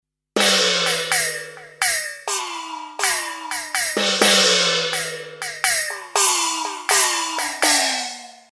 Набор маленьких гонгов?